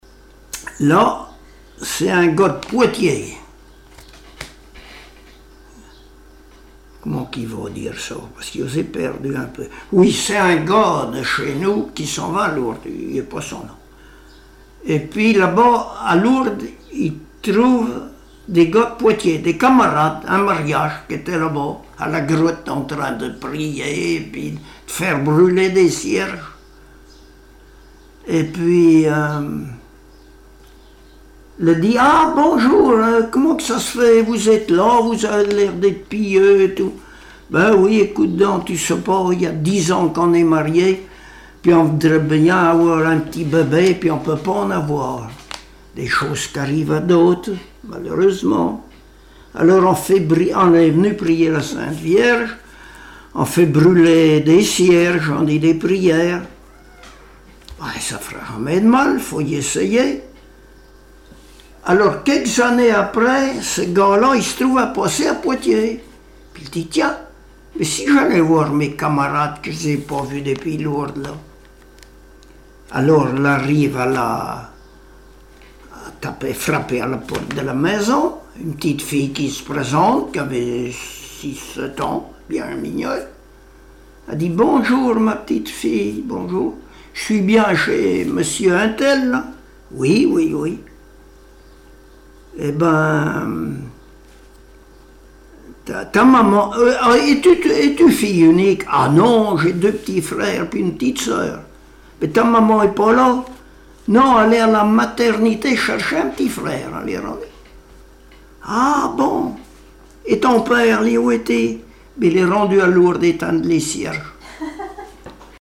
Genre sketch
Enquête Arexcpo en Vendée-Association Joyeux Vendéens
Catégorie Récit